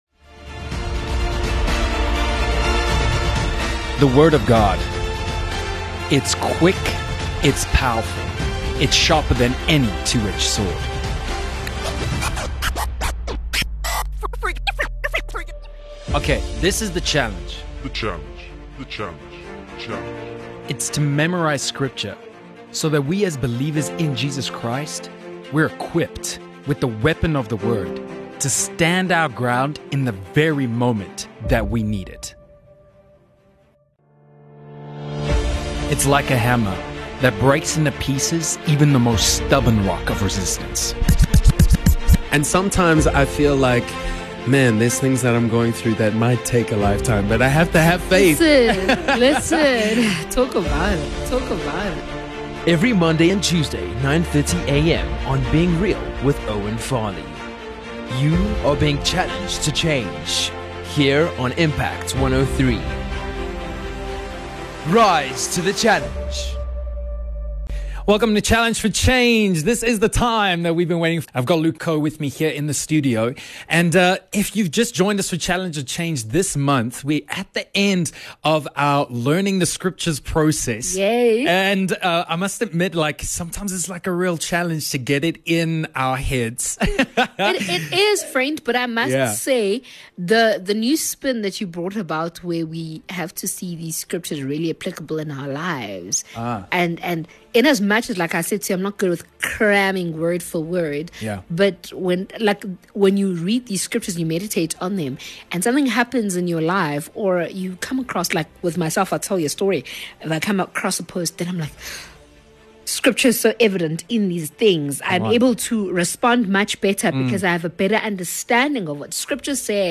The challenge is to memorize scripture so that we are equipped in the very moment that we need it. This challenge aims to encourage people to work on what matters. Filled with laughs, testimonies and the Word of God.